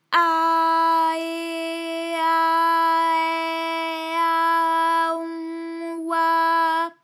ALYS-DB-001-FRA - First, previously private, UTAU French vocal library of ALYS
a_eh_a_ai_a_on_oi.wav